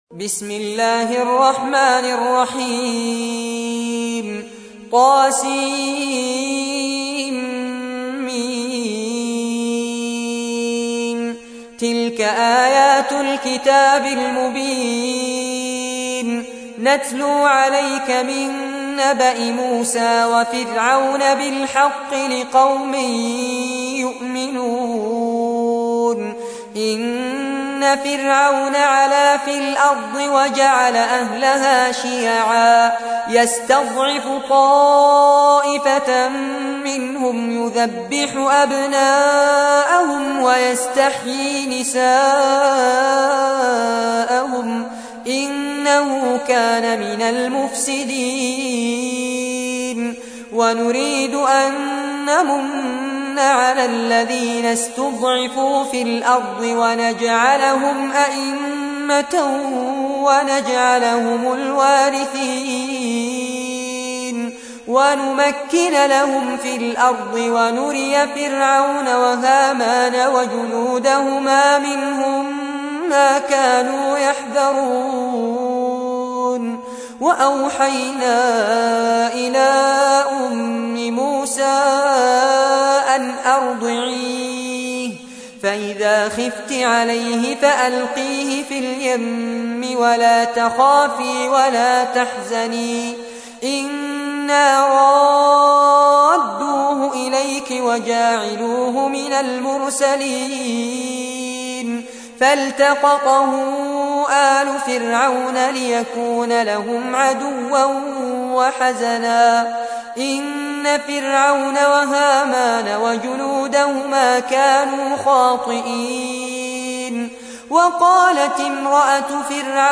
تحميل : 28. سورة القصص / القارئ فارس عباد / القرآن الكريم / موقع يا حسين